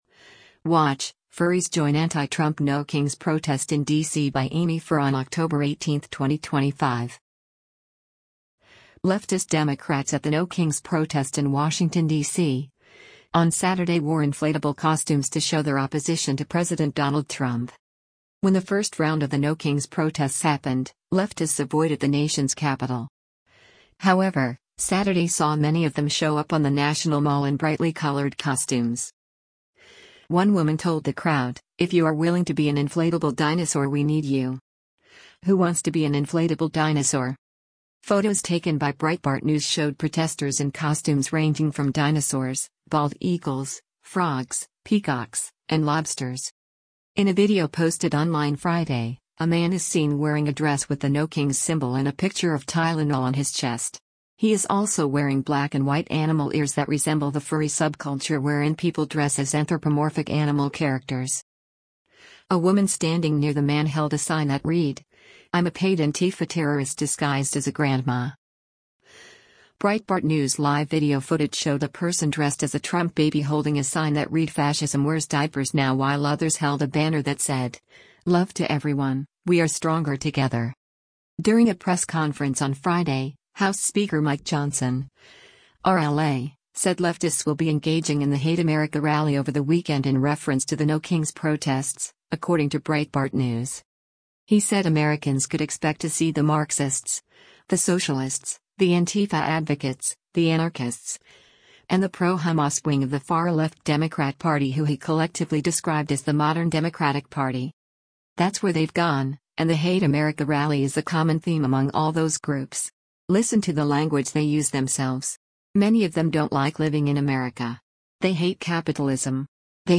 WATCH: Furries Join Anti-Trump ‘No Kings’ Protest in D.C.